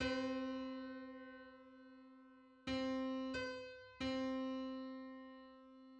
Just: 31:16 = 1145.04 cents.
Public domain Public domain false false This media depicts a musical interval outside of a specific musical context.
Thirty-first_harmonic_on_C.mid.mp3